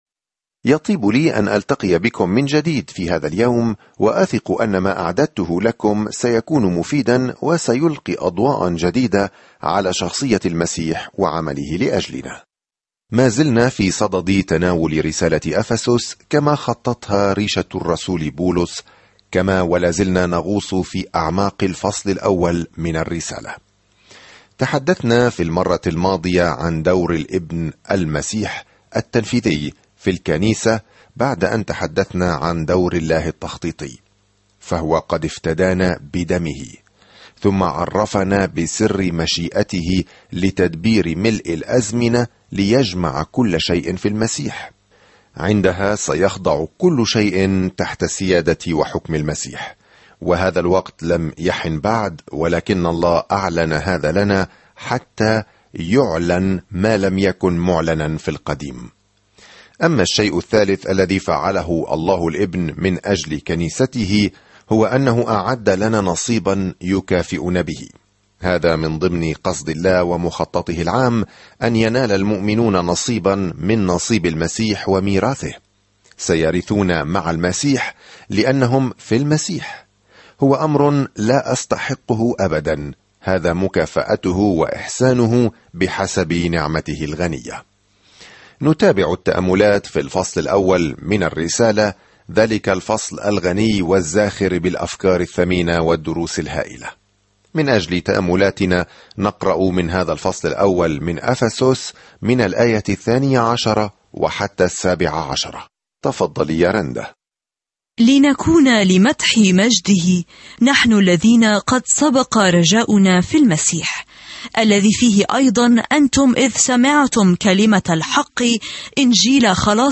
الكلمة أَفَسُسَ 12:1-17 يوم 7 ابدأ هذه الخطة يوم 9 عن هذه الخطة من الأعالي الجميلة لما يريده الله لأولاده، تشرح الرسالة إلى أهل أفسس كيفية السلوك في نعمة الله وسلامه ومحبته. سافر يوميًا عبر رسالة أفسس وأنت تستمع إلى الدراسة الصوتية وتقرأ آيات مختارة من كلمة الله.